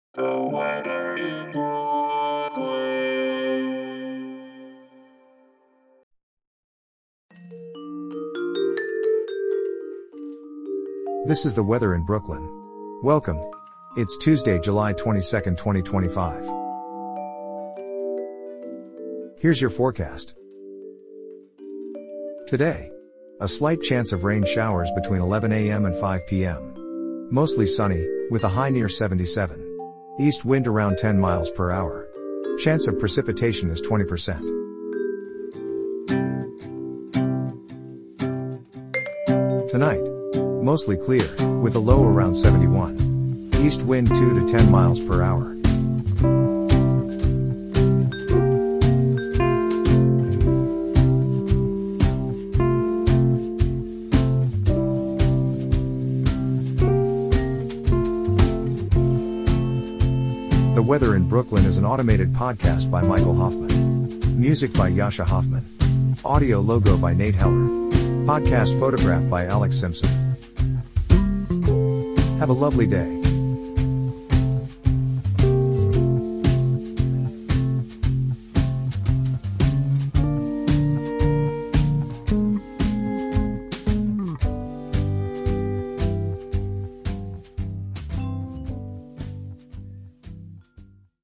is generated automatically.